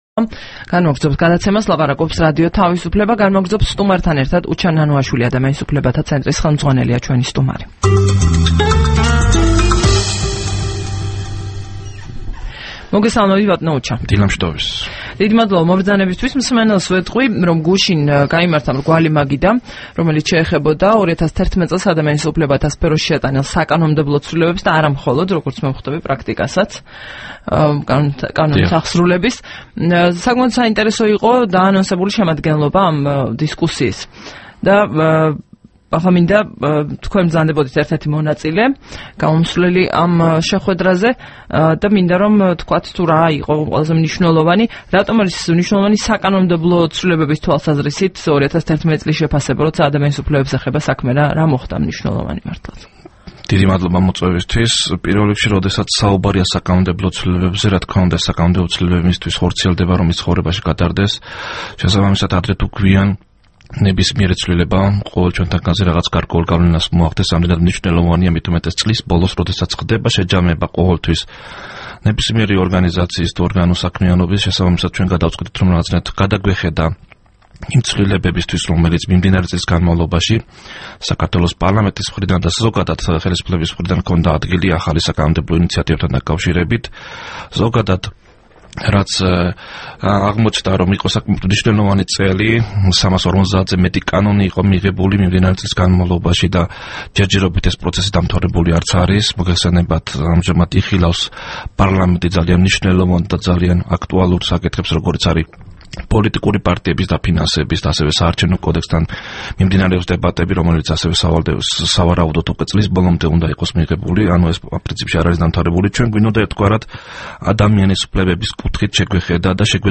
22 დეკემბერს რადიო თავისუფლების დილის გადაცემის სტუმარი იყო უჩა ნანუაშვილი, ადამიანის უფლებათა ცენტრის ხელმძღვანელი.
საუბარი უჩა ნანუაშვილთან